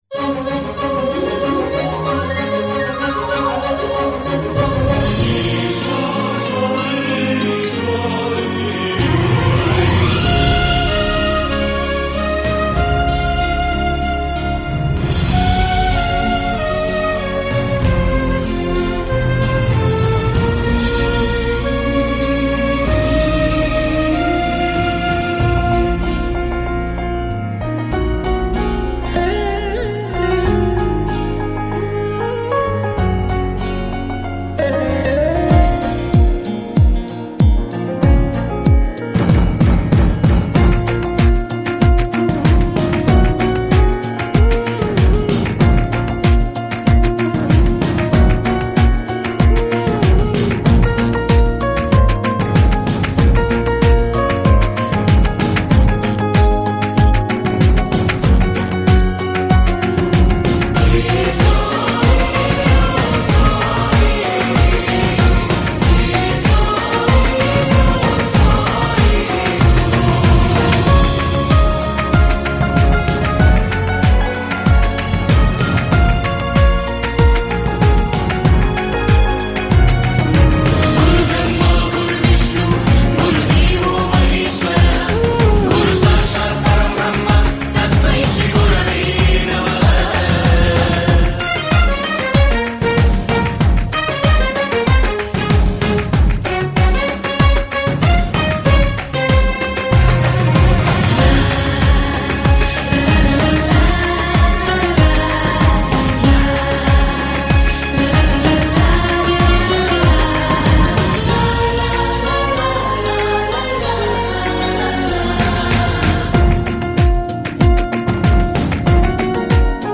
Singer: Instrumental